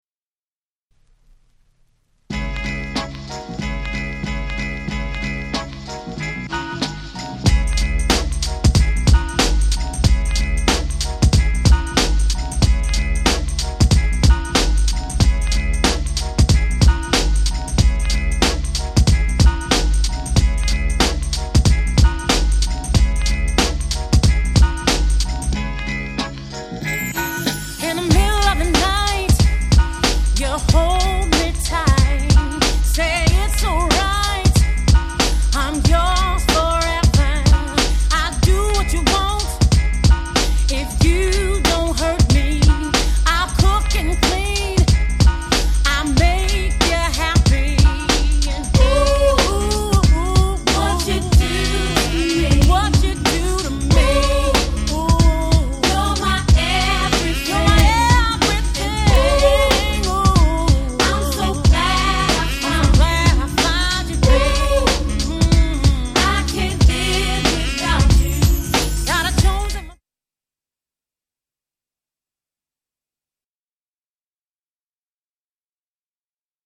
ヒップホップソウル